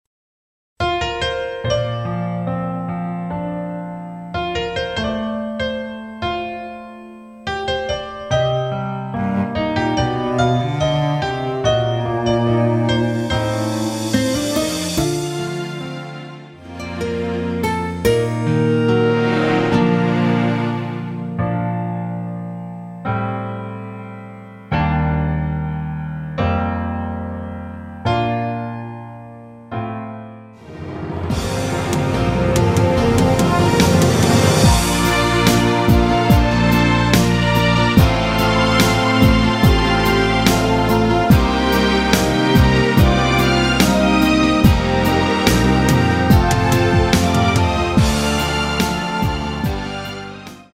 원곡이 높은편이라 대부분의 남성분이 부르실수 있는키로 제작 하였습니다.
원곡의 보컬 목소리를 MR에 약하게 넣어서 제작한 MR이며